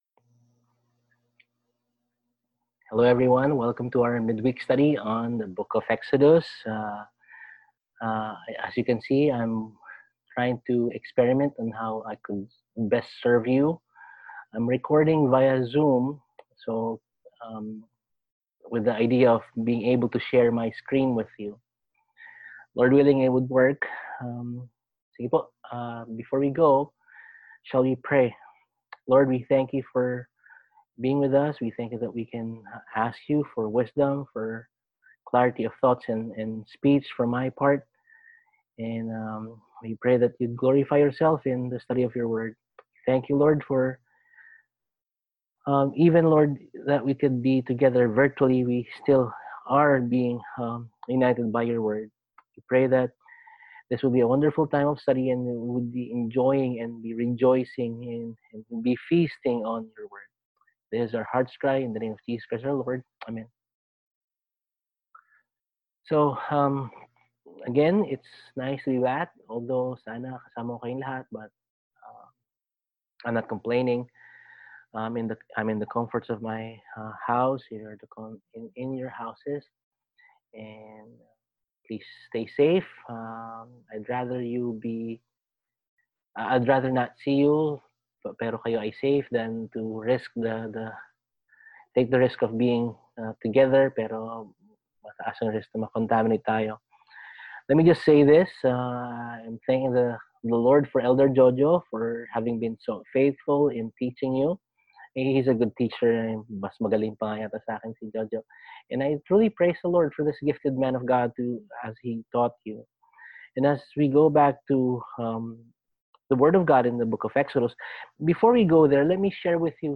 Service: Midweek